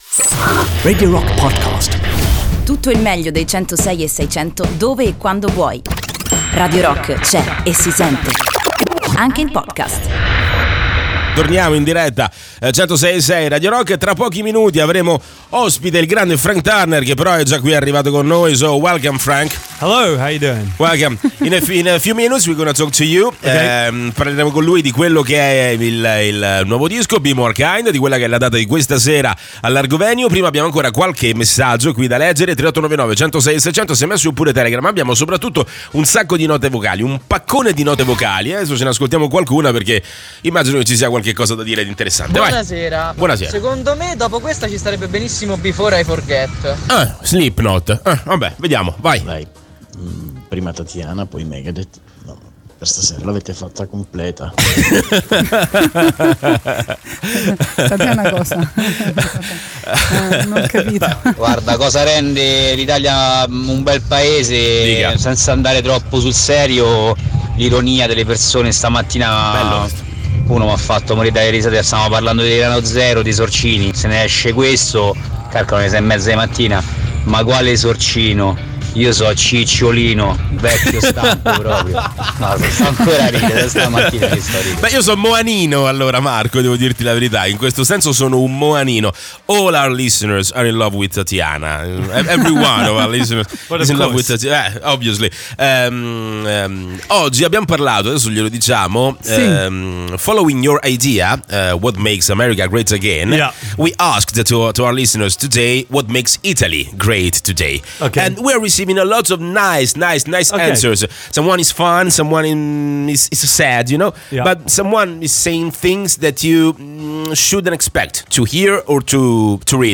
Intervista: Frank Turner presenta "Be more kind" (02-11-18)